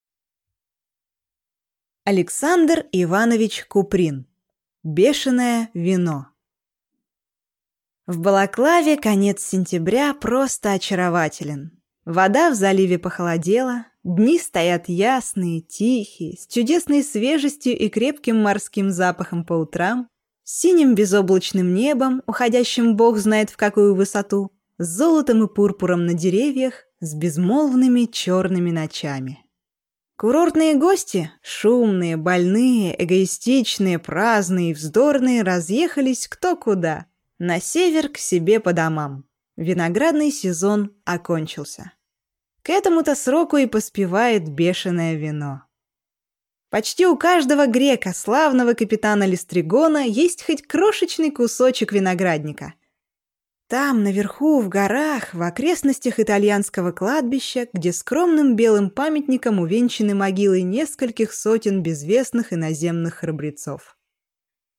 Аудиокнига Бешеное вино